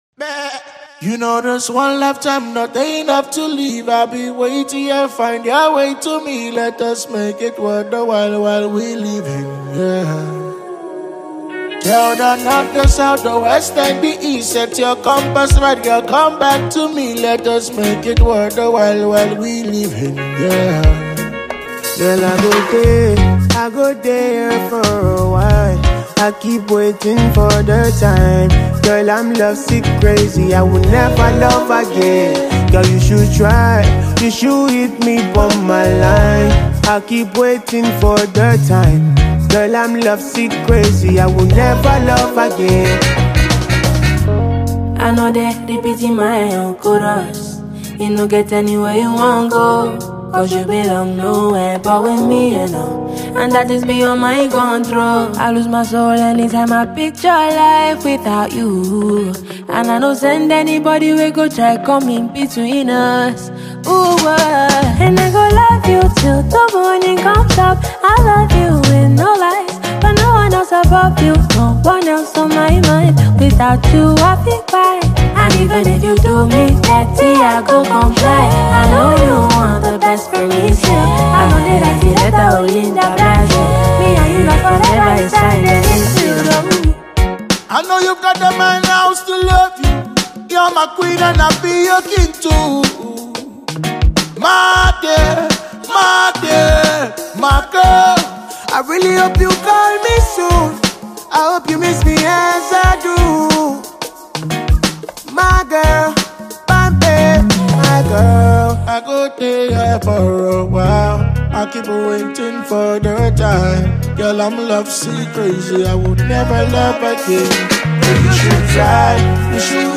is a soft, emotionally driven Afrobeats record